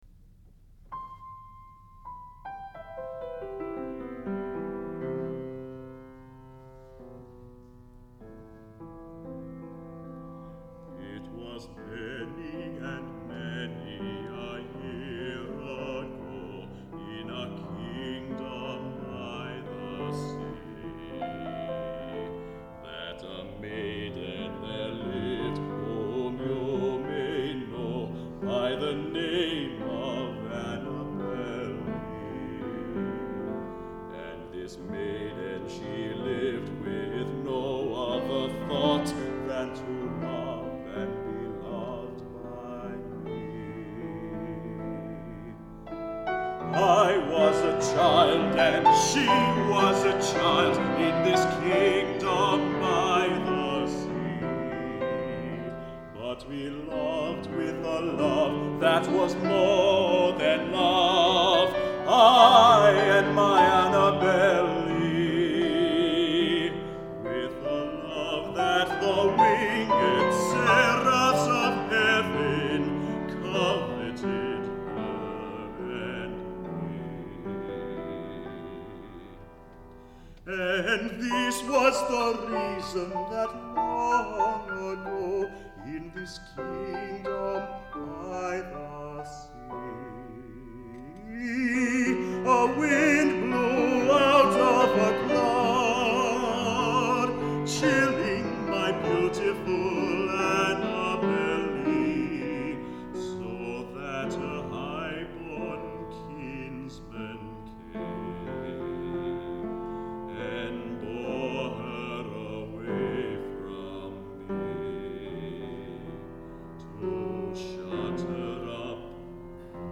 Warren Ellis, who is spending an entire week doing nothing but linking music, directs attention to a haunting setting of Edgar Allan Poe's poem